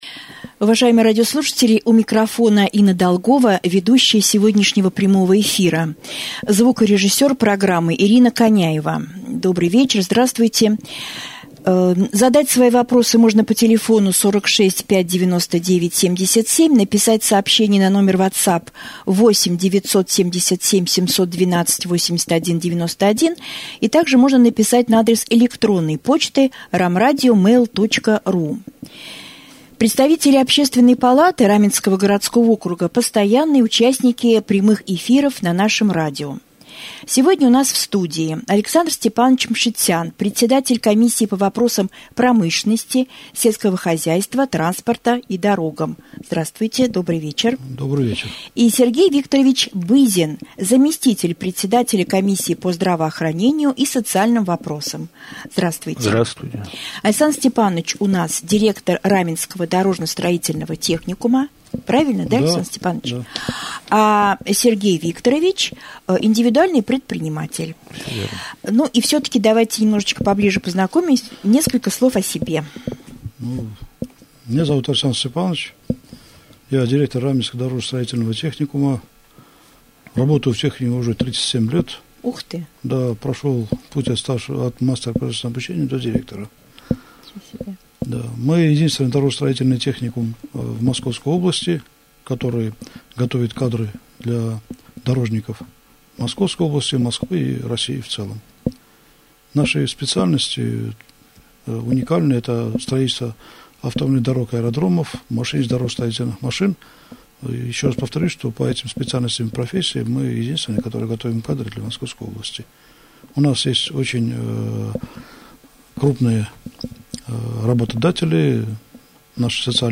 В прямом эфире Раменского радио